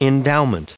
1294_endowment.ogg